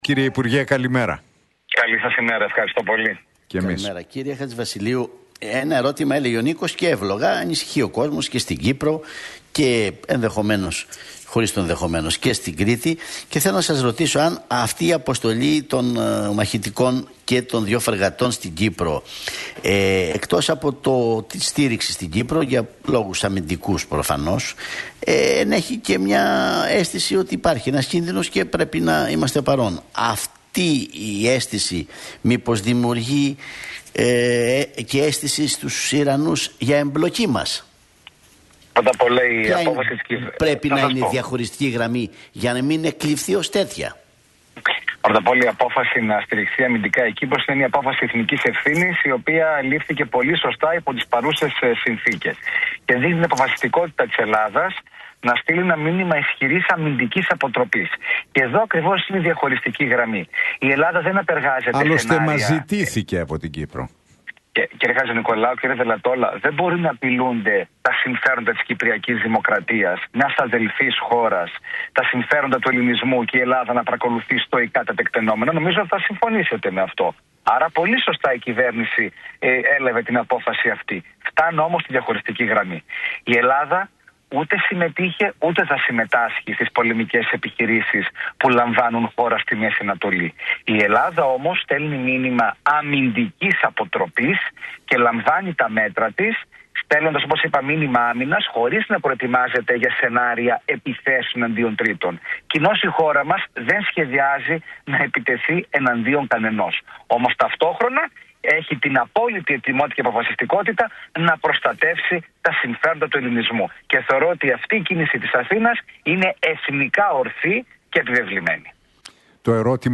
ο βουλευτής της ΝΔ, Τάσος Χατζηβασιλείου μιλώντας στον Realfm 97,8